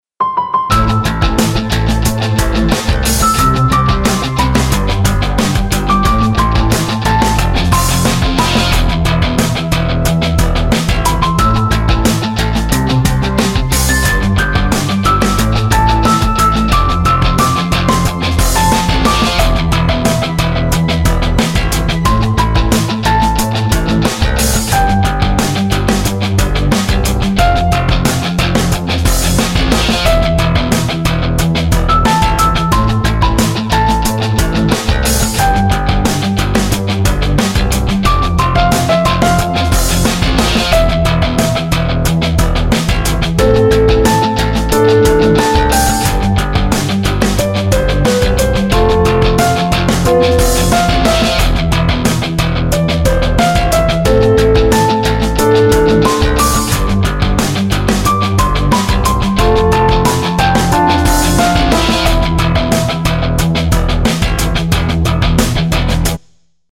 Инструментальный кусочек композиции